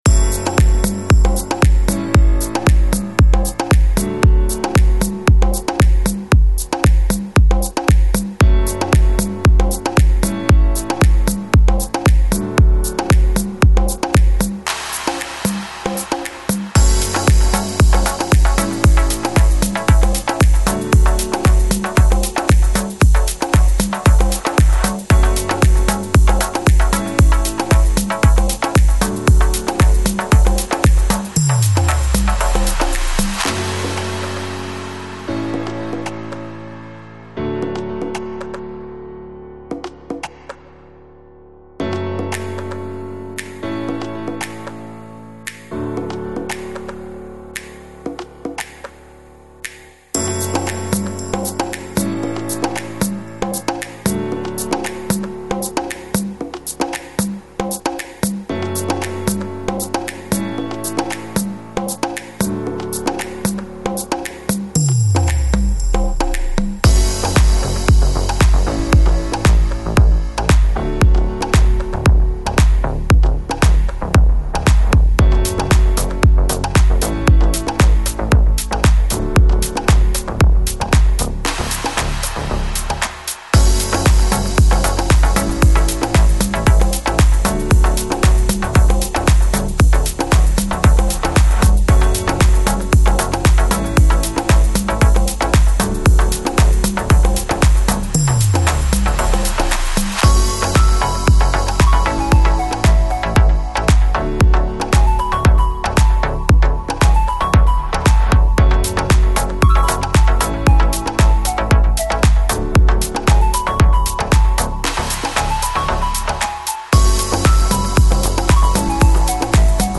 Electronic, Lounge, Chill Out, Deep House